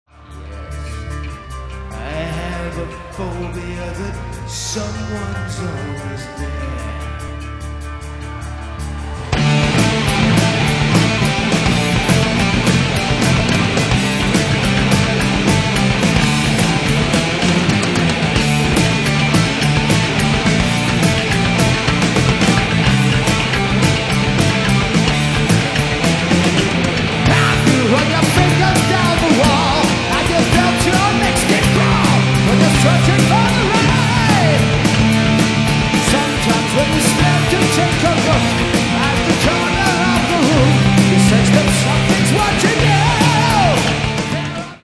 All the sound clips are live.